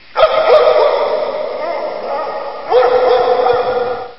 Звук далекий лай собаки.